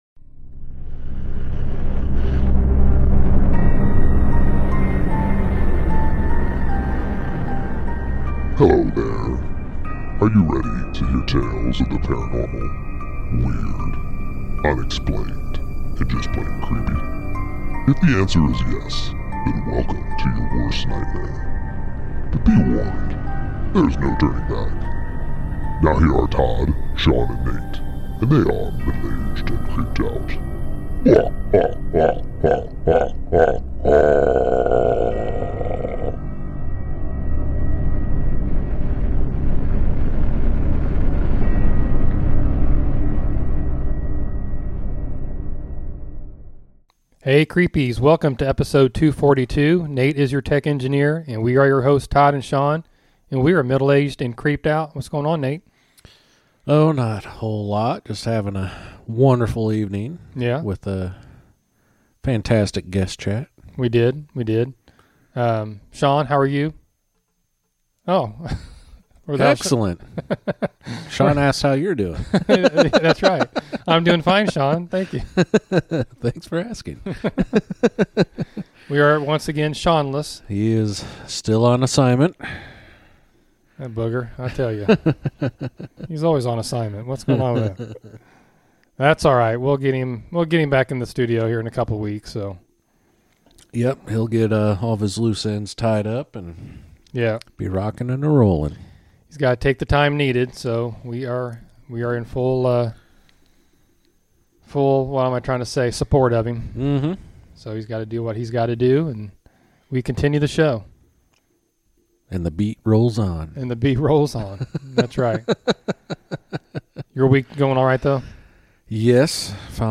Middle Aged And Creeped Out podcast / Guest Chat